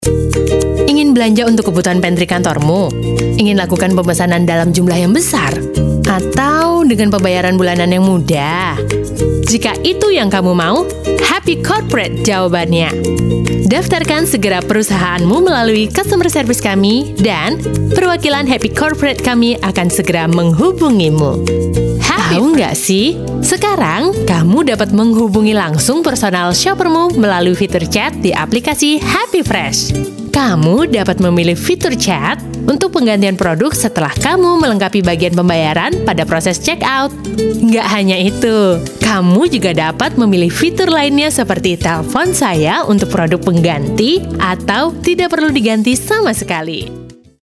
Casual Indonesia IVR Radio JKS015_INDO_CASUAL JKS015_INDO_IVR